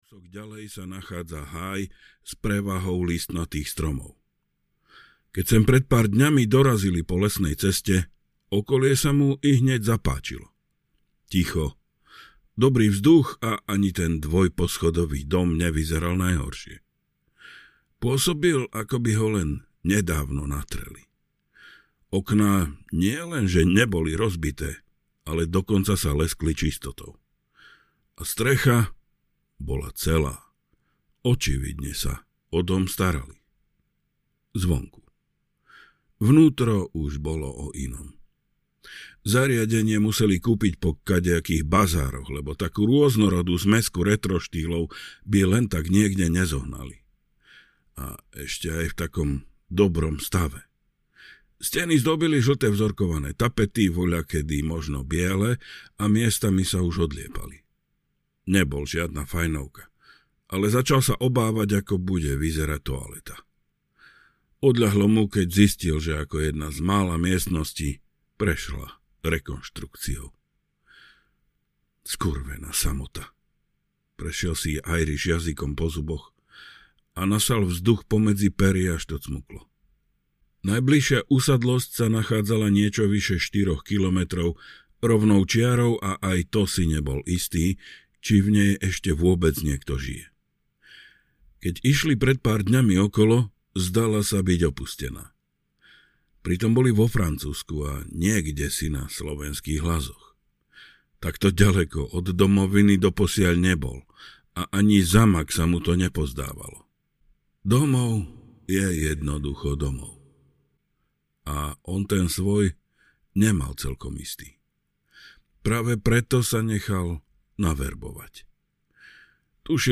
Trestanec audiokniha
Ukázka z knihy